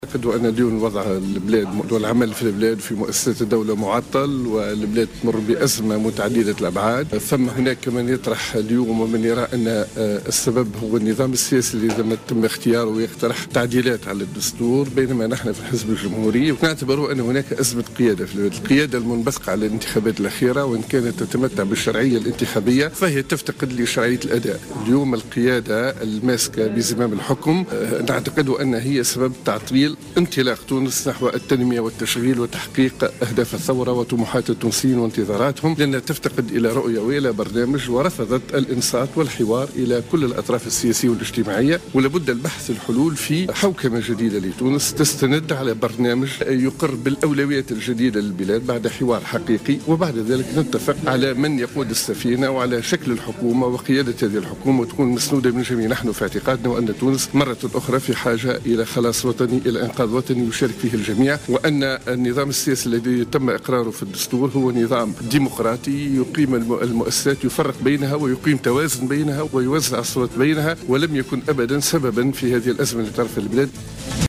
قال الناطق باسم الحزب الجمهوري، عصام الشابي اليوم خلال ندوة صحفية عقدها الحزب إن تونس تعيش أزمة قيادة وإنه لابد من برنامج إنقاذ وطني تشارك فيه جميع الأطراف.